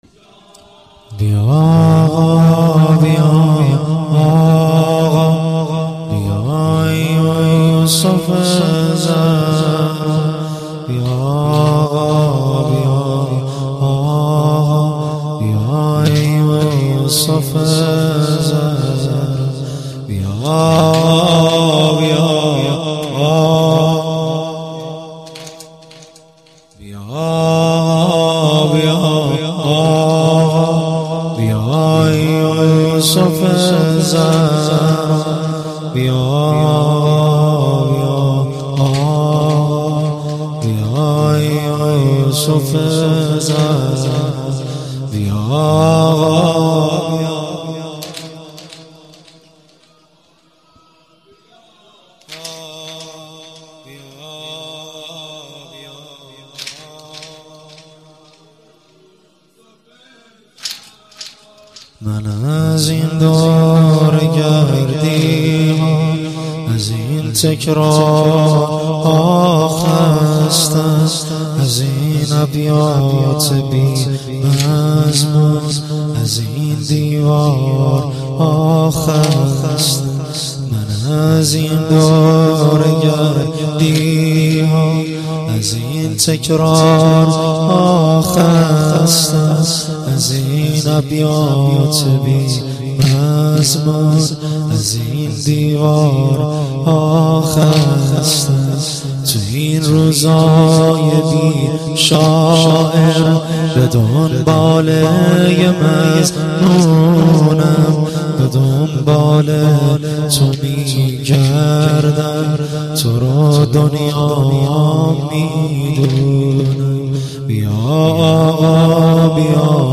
واحد - شهادت حضرت میثم تمار ره 1393 مداح